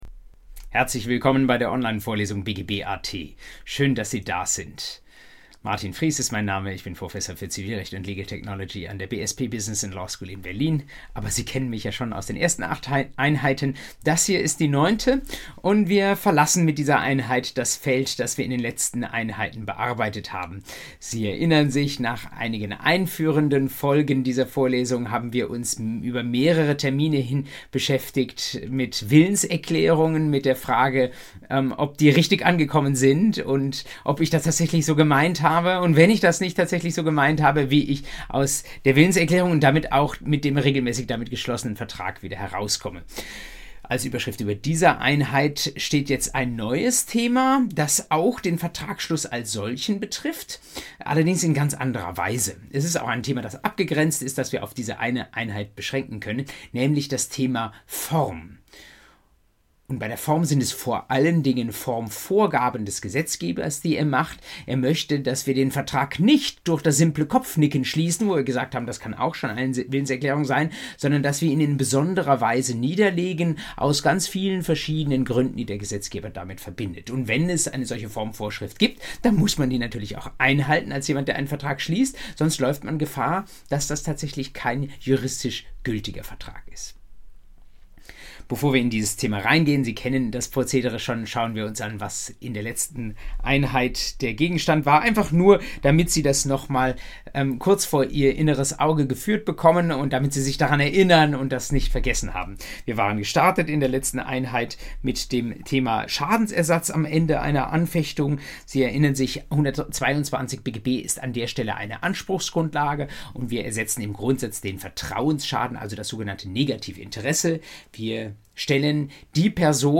BGB AT Folge 9: Formvorgaben für Rechtsgeschäfte ~ Vorlesung BGB AT Podcast